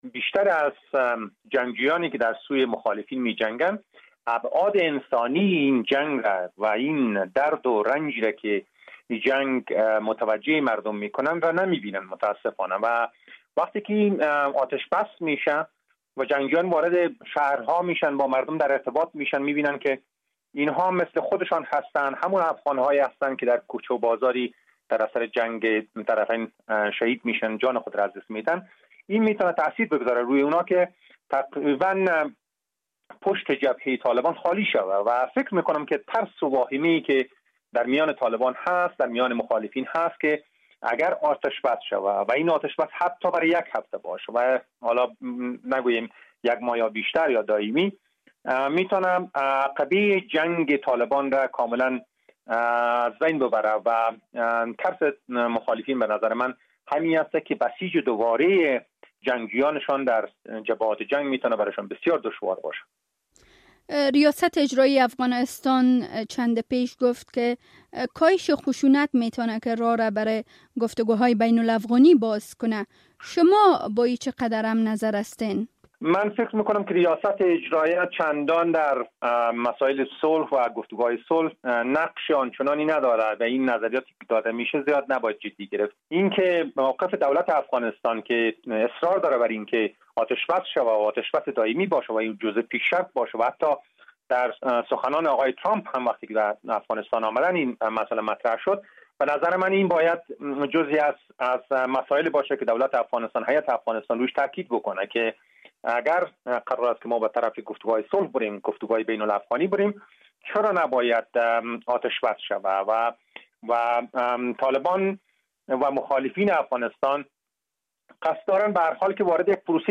مصاحبه - صدا
مصاحبه